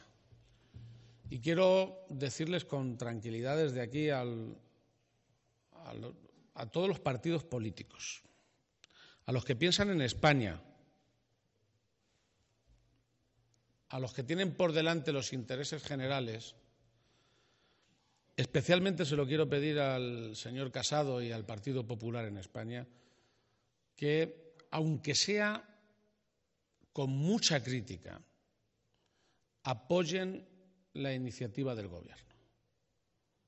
>> En la firma del Plan de Medidas Extraordinarias para la Recuperación Económica